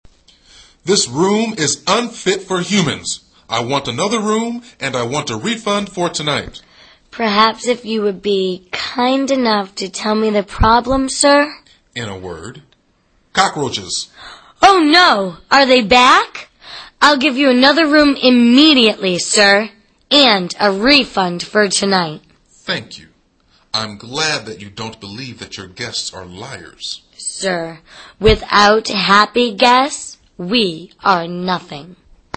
旅馆英语对话-Cockroach Problem(11) 听力文件下载—在线英语听力室